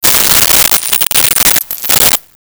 Coins Thrown 03
Coins Thrown 03.wav